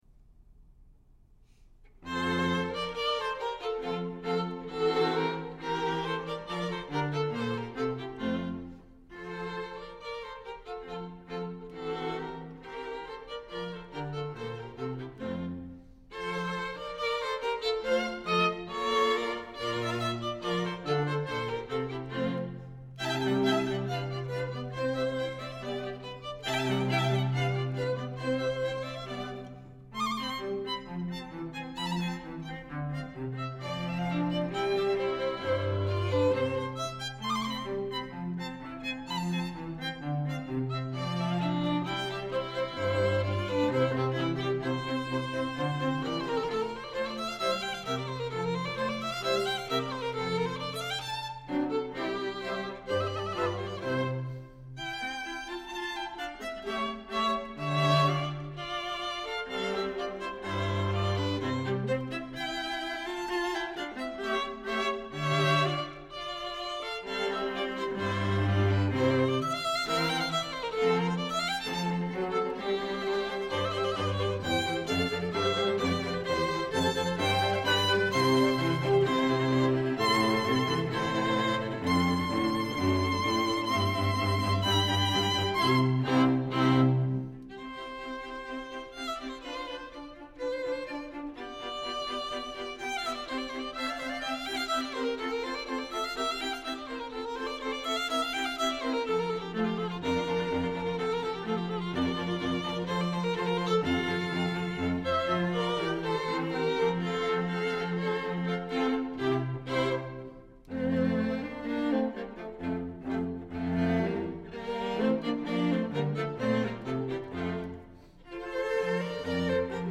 Elysium String Quartet – performing throughout the Lehigh Valley, Delaware Valley, and Philadelphia, PA